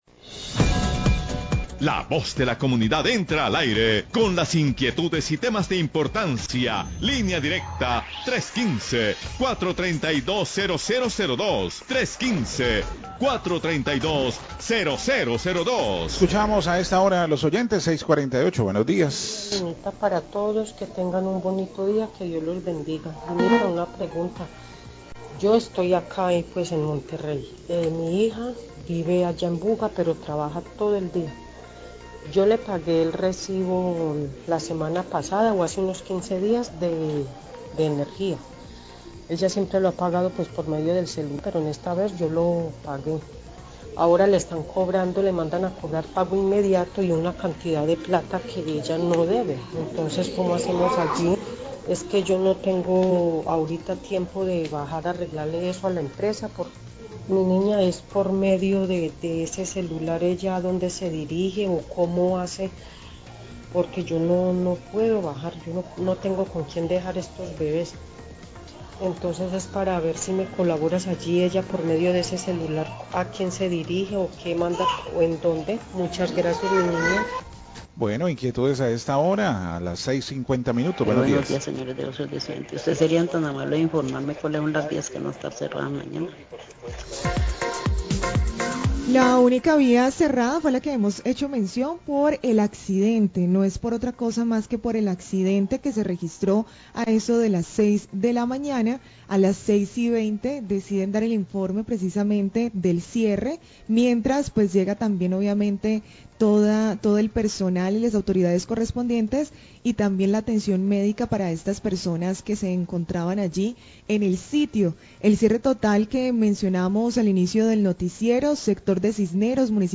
Oyente señala que le llega cobro por valor que no debe y pregunta cómo ponerse en contacto con Celsia, Voces de Occidente, 649am
Radio